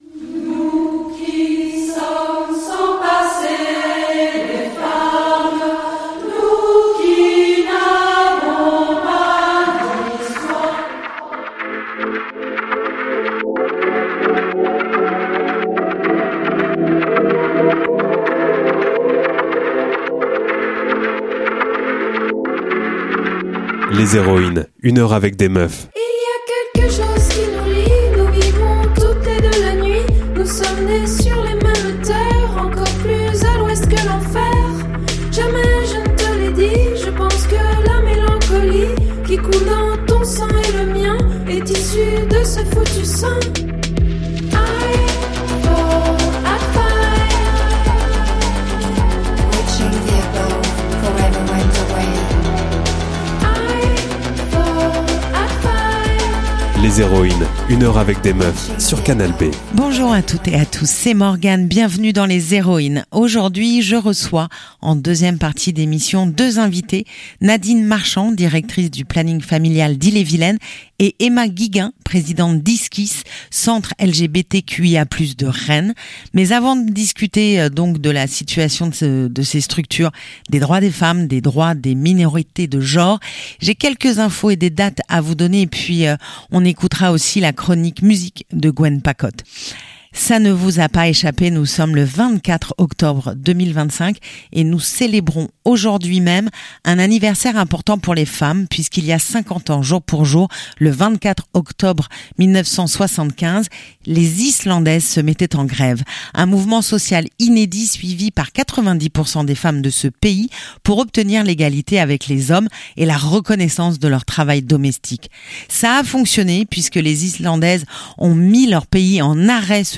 La chronique musique